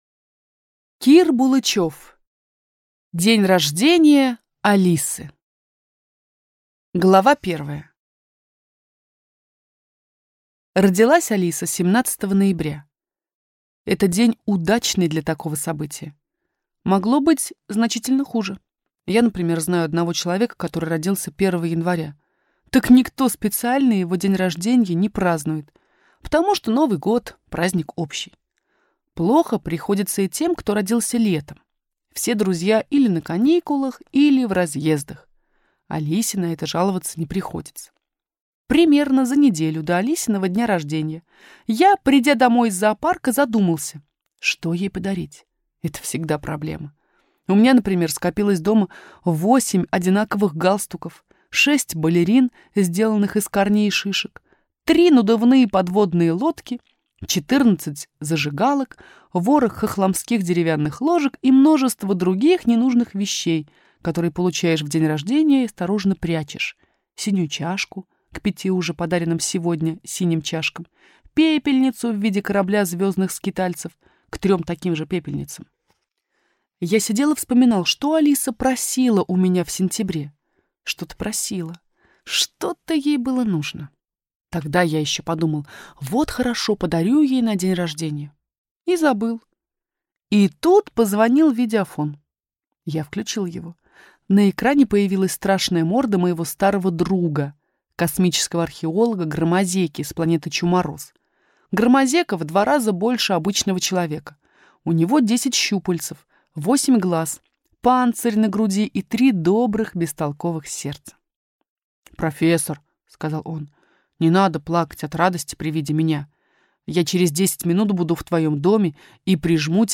Аудиокнига День рождения Алисы - купить, скачать и слушать онлайн | КнигоПоиск